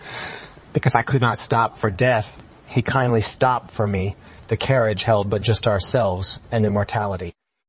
tortoise-tts - (A fork of) a multi-voice TTS system trained with an emphasis on quality